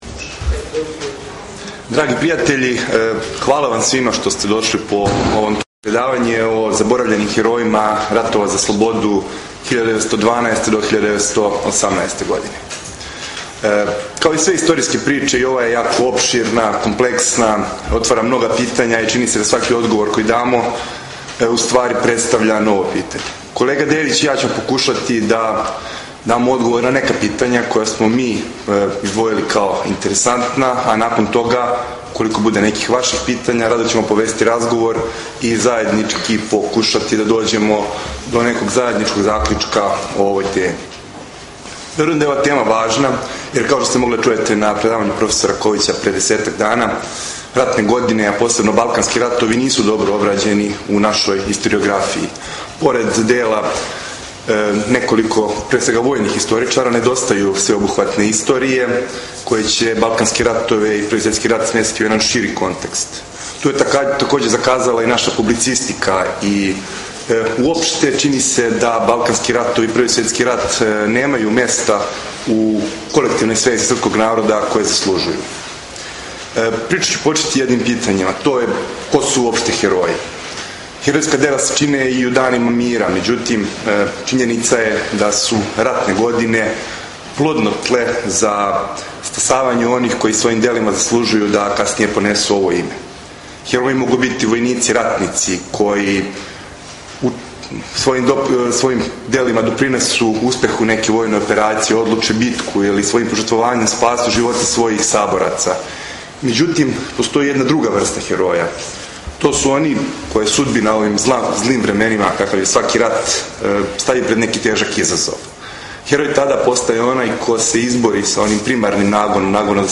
30.04.2013 Заборављени хероји Последње предавање у оквиру пратећег програма изложбе "Јунаштвом у славу", одржано је у Великој галерији Дома Војске Србије. Чланови Српског академског круга подсетили су посетиоце на заборављене хероје из српске историје.